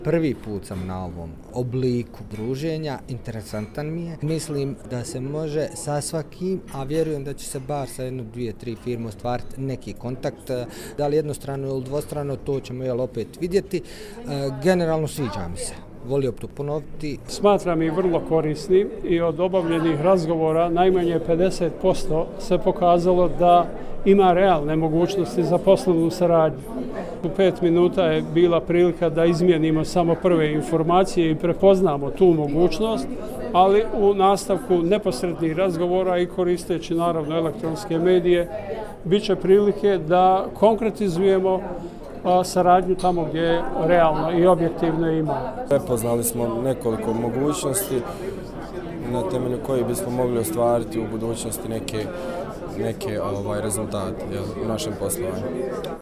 Građani o poslovnom umrežavanju